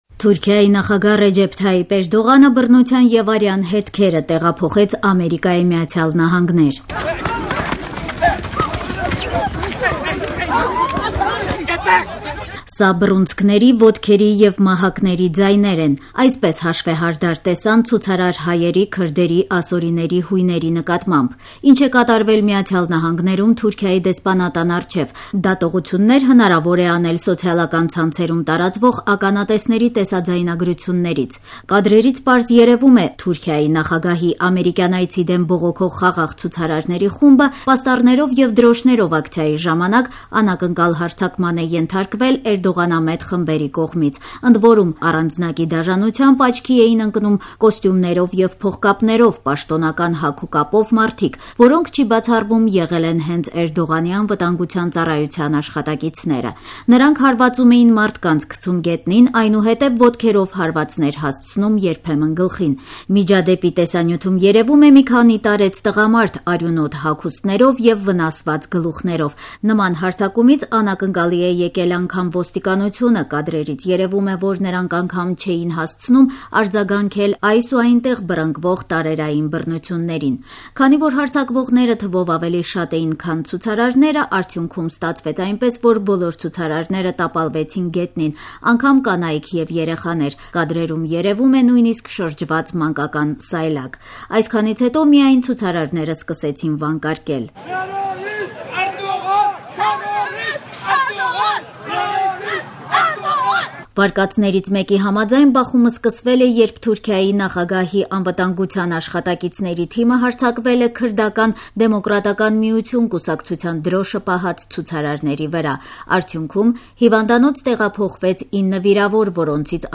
Սա բռունցնքների, ոտքերի եւ մահակների ձայներ են: Այսպես հաշվեհարդար տեսան ցուցարար հայերի, քրդերի, ասորիների, հույների նկատմամբ: Ի՞նչ է կատարվել Միացյալ Նահանգներում Թուրքիայի դեսպանատան առջեւ՝ դատողություններ հնարավոր է անել սոցիալական ցանցերում տարածվող ականատեսների տեսաձայնագրությունների միջոցով: Կադրերից պարզ երեւում է՝ Թուրքիայի նախագահի ամերիկյան այցի դեմ բողոքող խաղաղ ցուցարարների խումբը պաստառներով եւ դրոշներով ակցիայի ժամանակ անակնկալ հարձակման է ենթարկվել էրդողանամետ խմբերի կողմից: